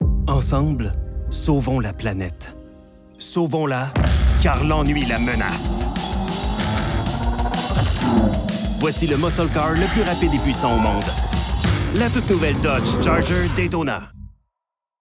Publicity - Voix Person./Ann 1 Publicity - Voix Person./Ann 2 Publicity - Voix Annonceur 3 Publicity - Voix Person./Ann 4 Publicity - Voix Annonceur 5 Publicity - Voix Person./Ann 6 Publicity - Voix Person./Ann 7